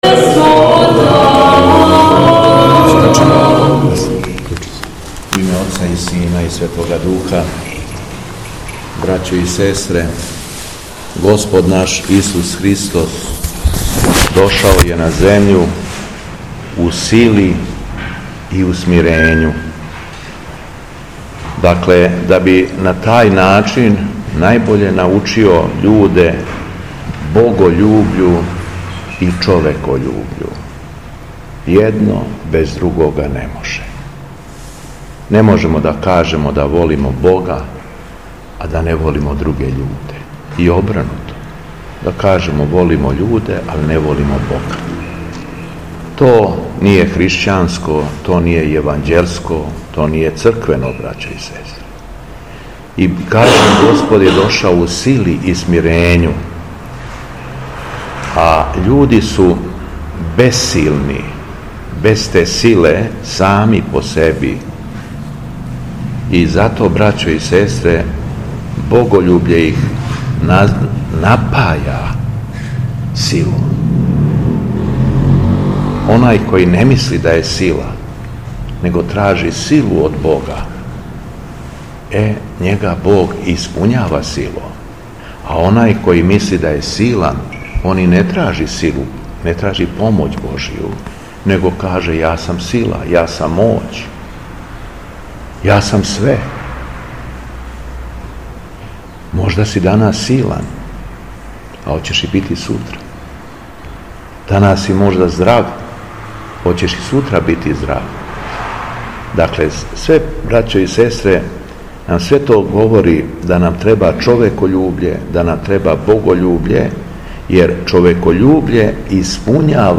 Беседа Његовог Високопреосвештенства Митрополита шумадијског г. Јована
После прочитаног јеванђелског зачала Високопреосвећени Владика се обратио верном народу беседом: